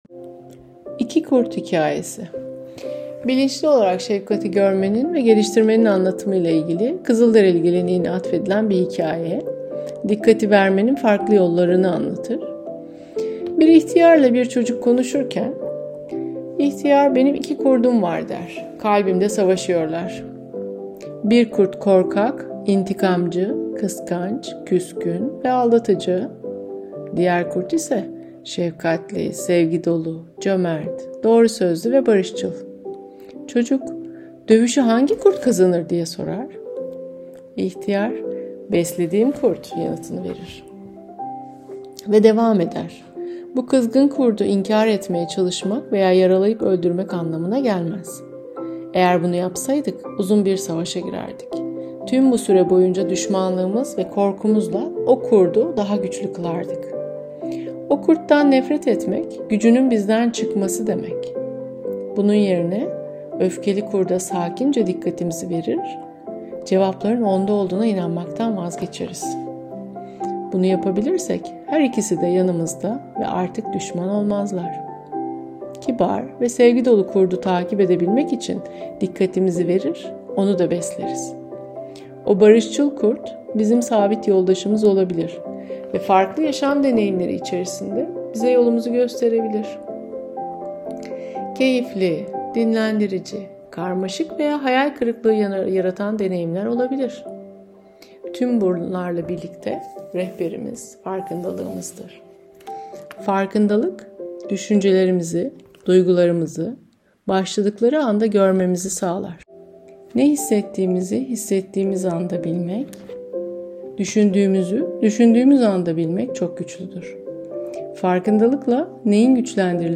Hikayemizi soldaki düğmeye tıklayarak dinleyebilirsiniz.